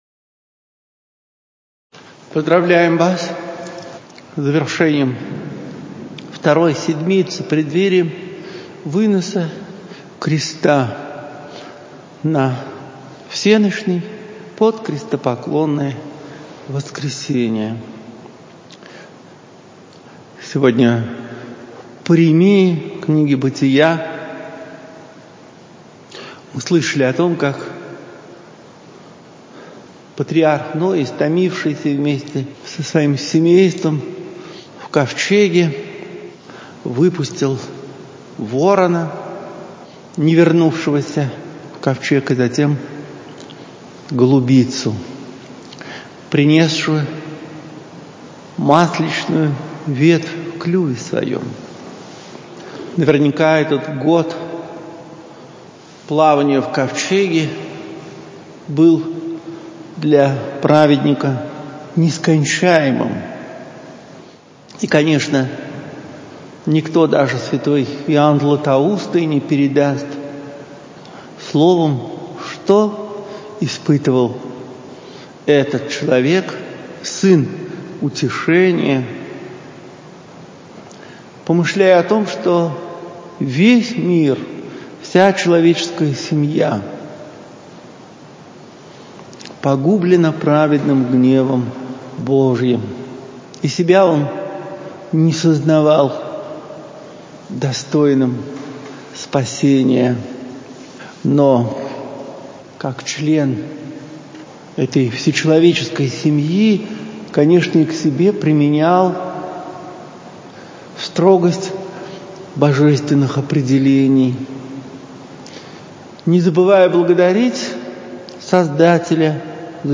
В храме Всех Святых Алексеевского ставропигиального женского монастыря, 2 апреля 2021, на литургии.